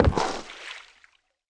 Block Boat Land Sound Effect
Download a high-quality block boat land sound effect.
block-boat-land.mp3